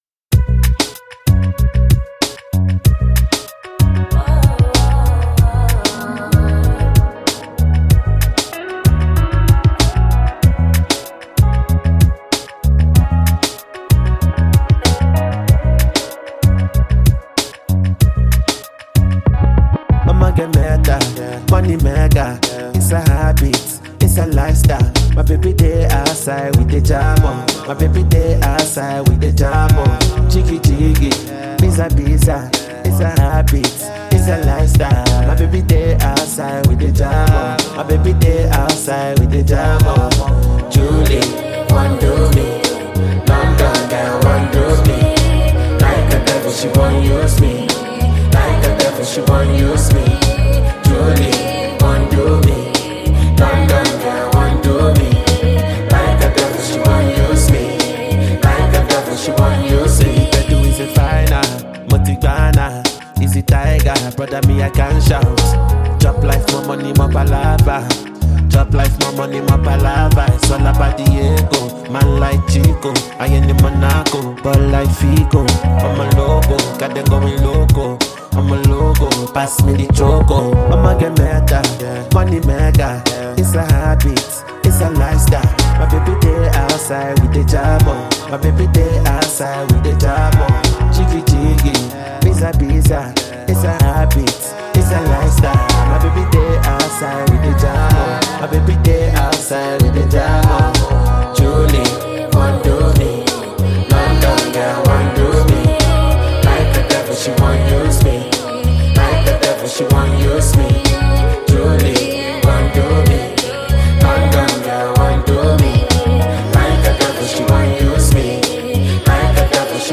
Genero: Afrobeat